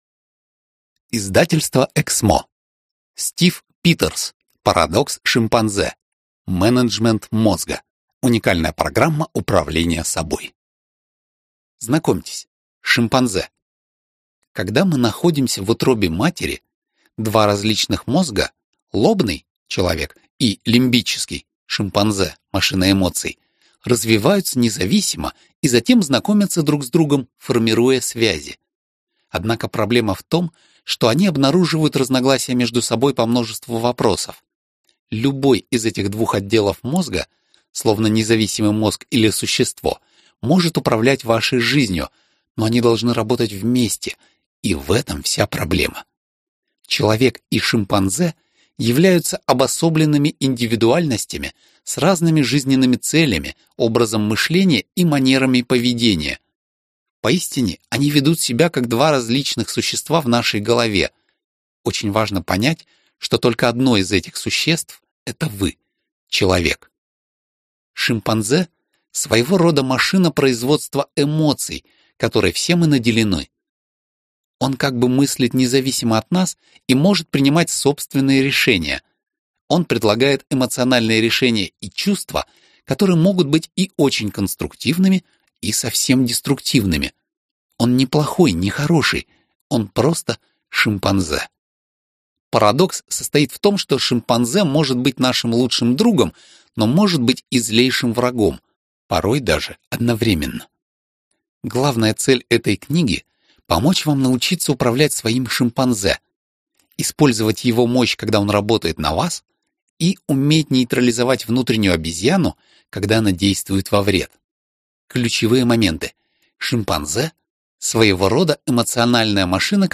Аудиокнига Парадокс Шимпанзе. Менеджмент мозга | Библиотека аудиокниг